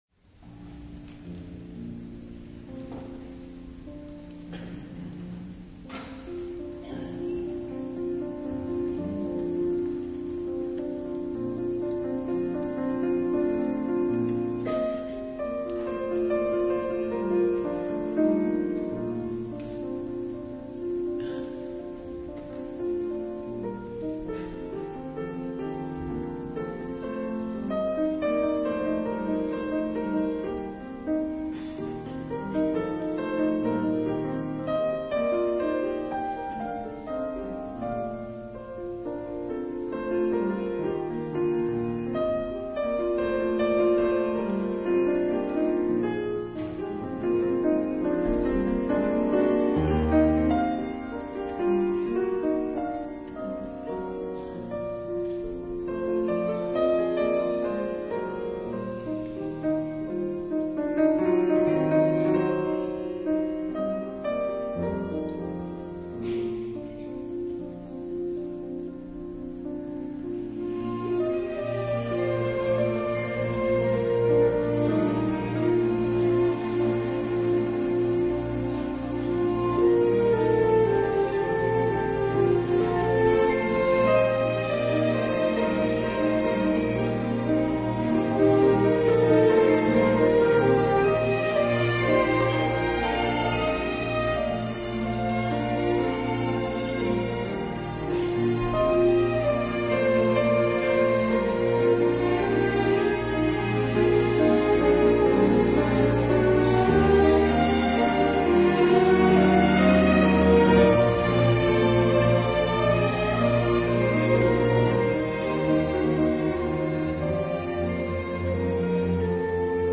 piano
Grabación realizada por el equipo de exteriores de las radios públicas el 28 de junio en el Auditorio Nacional Adela Reta.